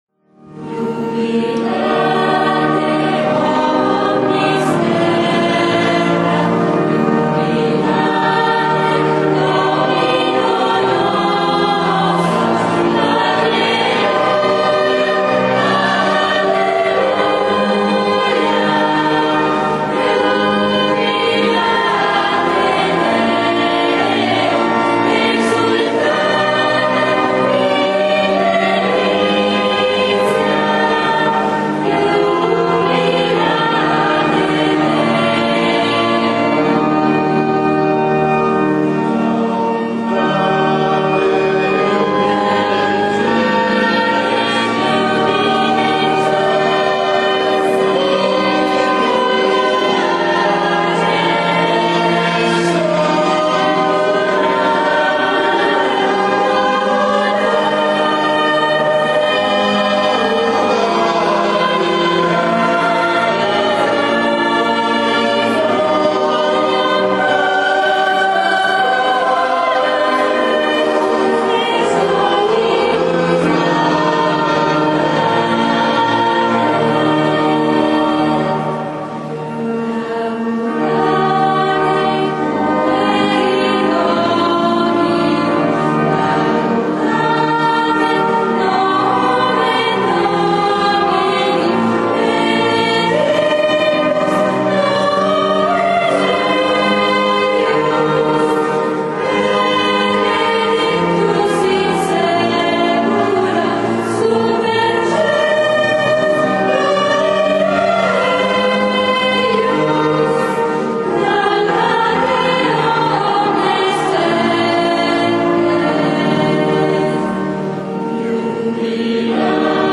Celebrazione della Resurrezione del Signore